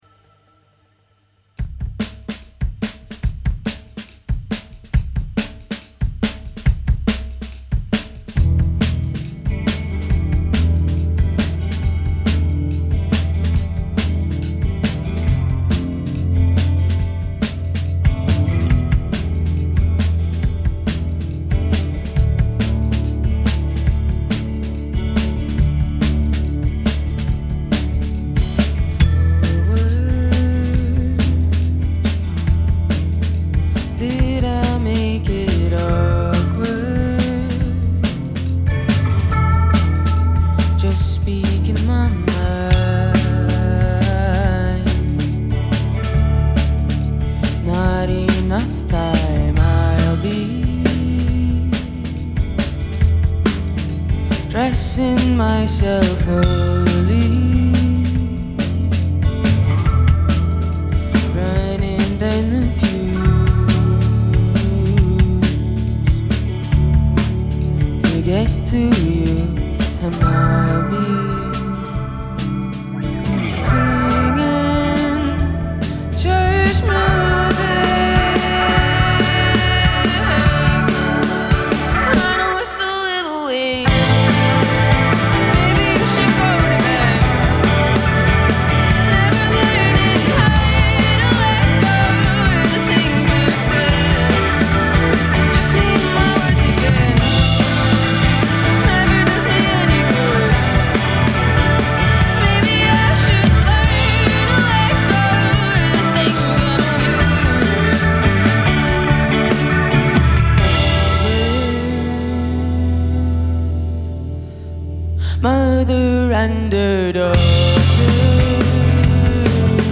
broadcast live and recorded on December 6th.